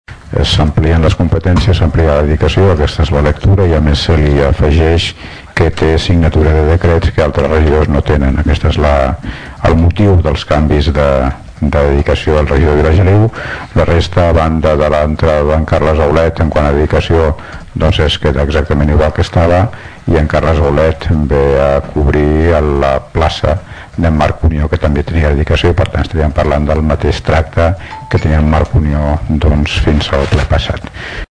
L’alcalde de Tordera, Joan Carles Garcia va justificar l’augment de dedicació dels regidors de govern.
retribucions-alcalde.mp3